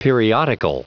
Prononciation du mot periodical en anglais (fichier audio)
Prononciation du mot : periodical